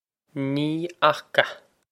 Pronunciation for how to say
Nee okh-a
This is an approximate phonetic pronunciation of the phrase.